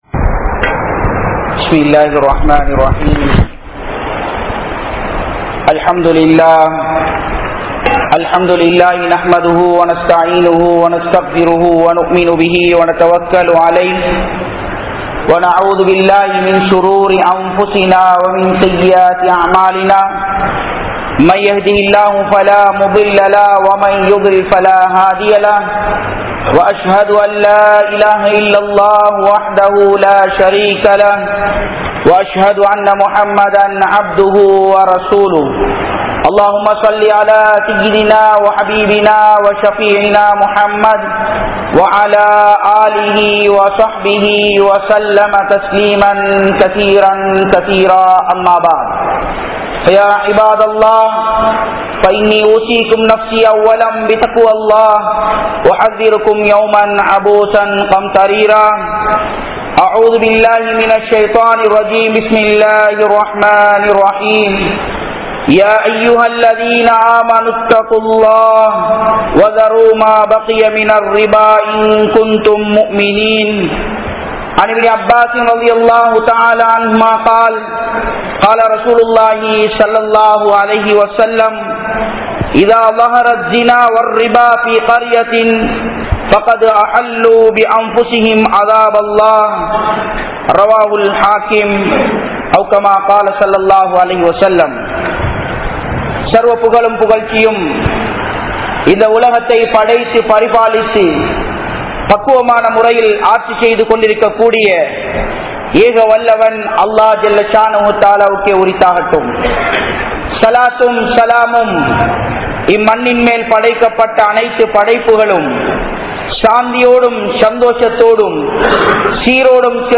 Vatti Edupathanaal Varak Koodia Thandanaihal (வட்டி எடுப்பதனால் வரக் கூடிய தண்டனைகள்) | Audio Bayans | All Ceylon Muslim Youth Community | Addalaichenai